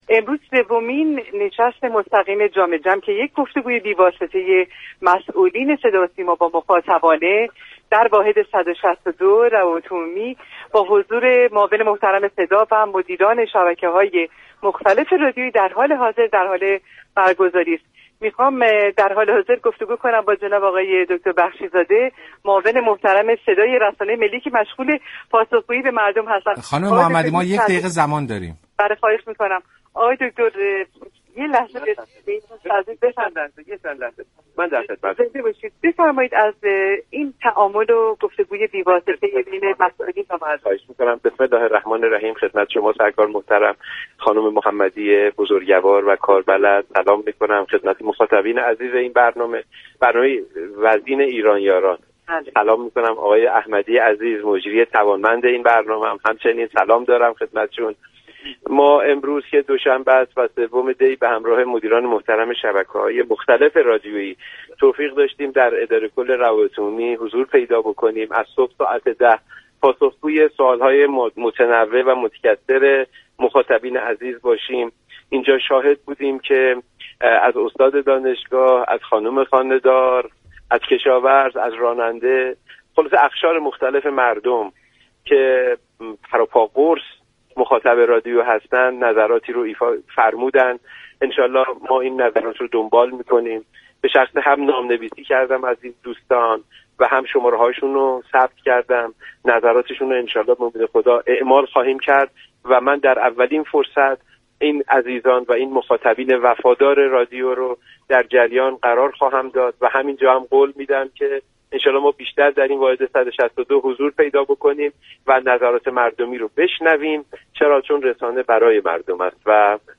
گفت و گو كرد